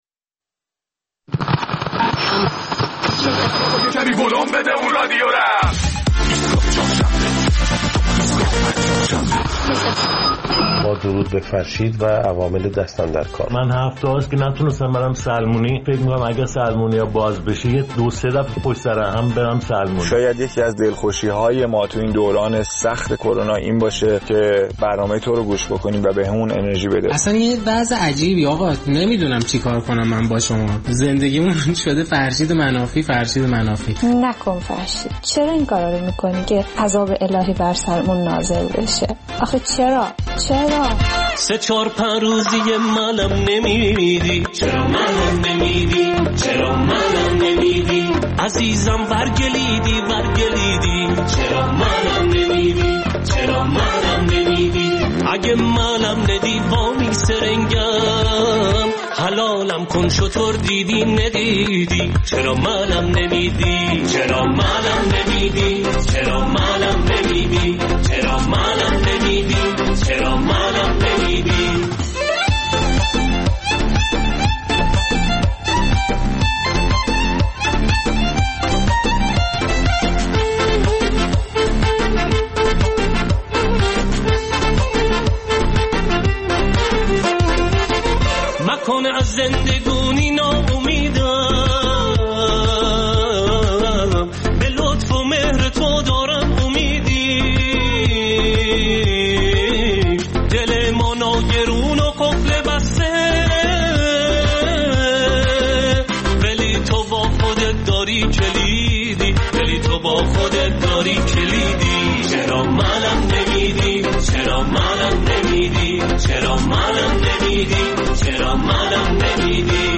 در این برنامه نظرات شنوندگان ایستگاه فردا را در مورد افراط.